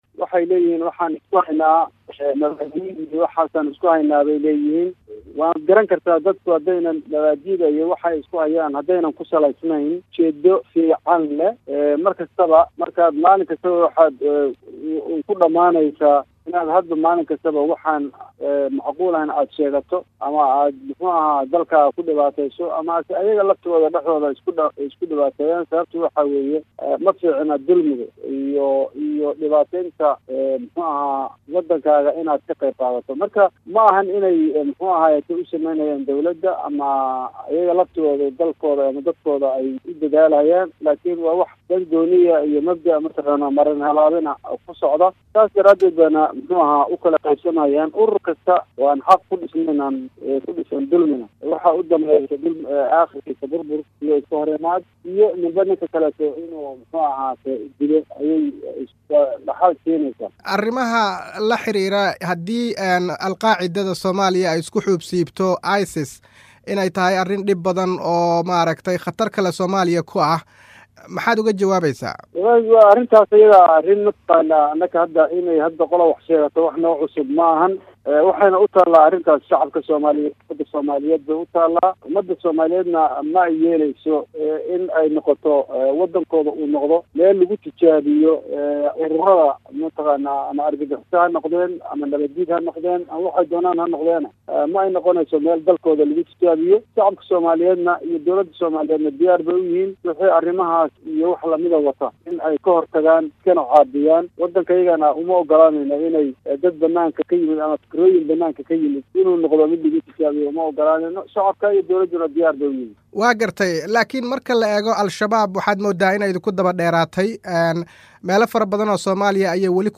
Wareysi: Wasiirka Gaashaandhigga Somalia